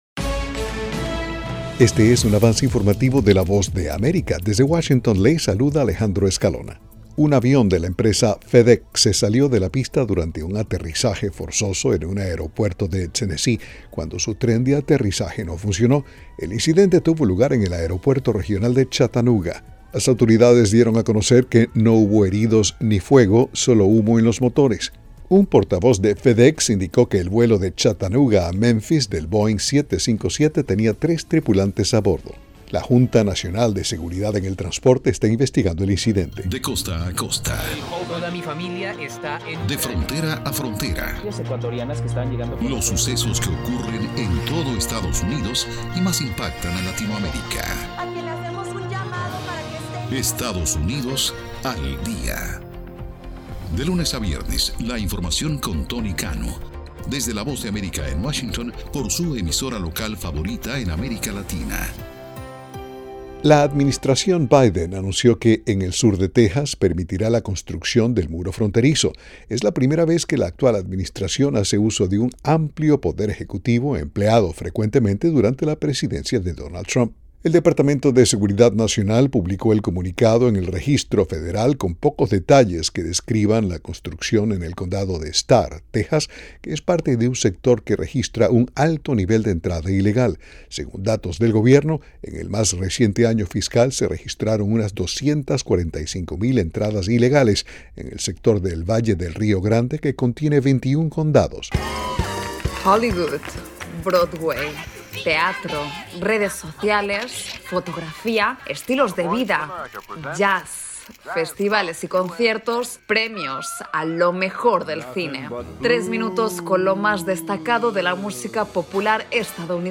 Este es un avance informativo presentado por la Voz de América en Washington.